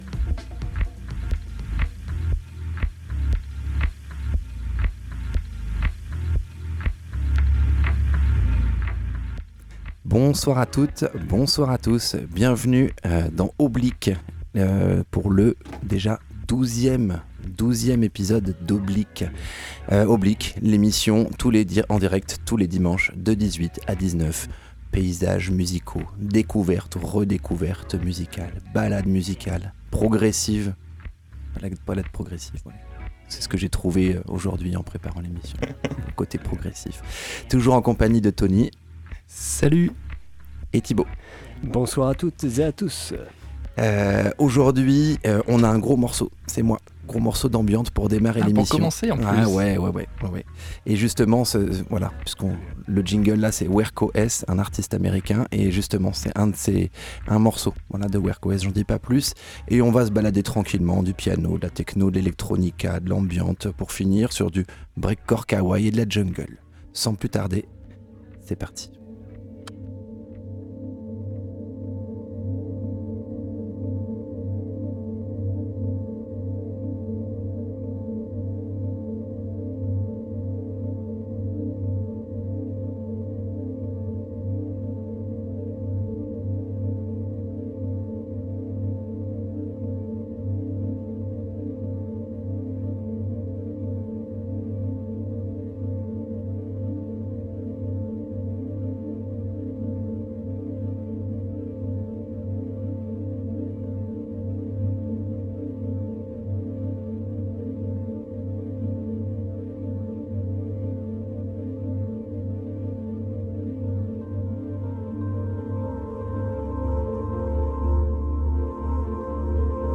DOWNTEMPO ELECTRO